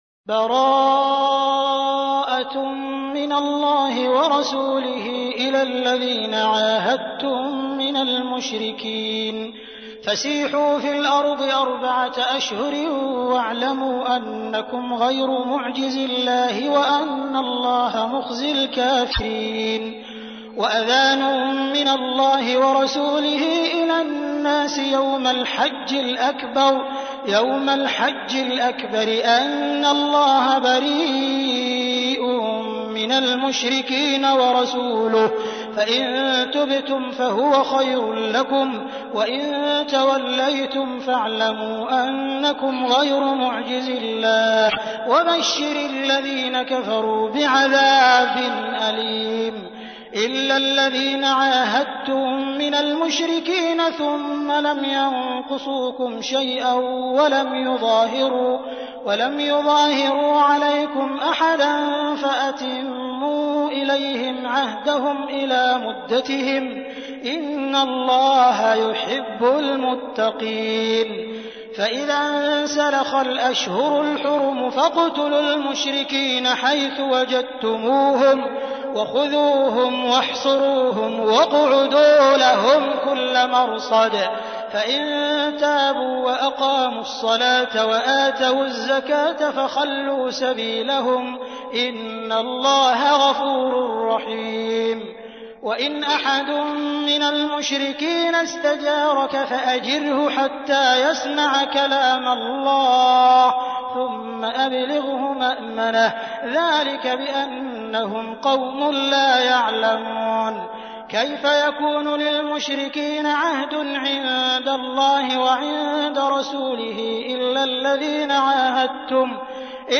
تحميل : 9. سورة التوبة / القارئ عبد الرحمن السديس / القرآن الكريم / موقع يا حسين